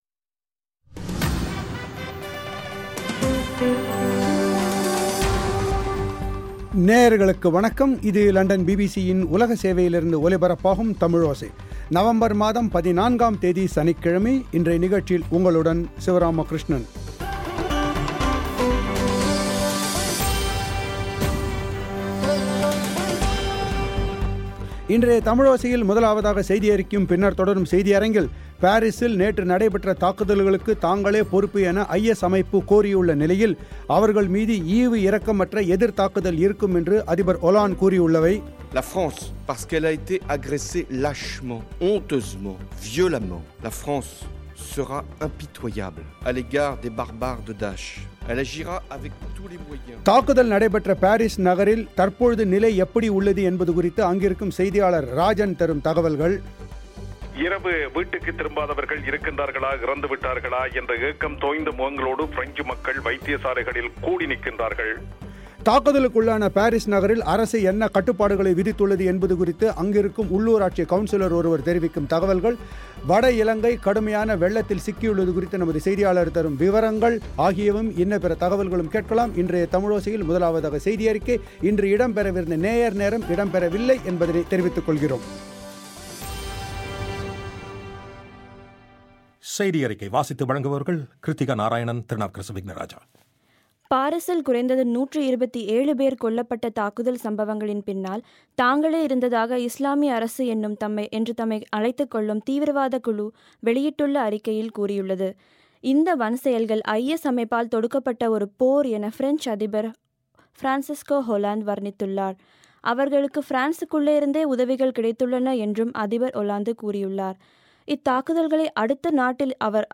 முதலாவதாக செய்தியறிக்கை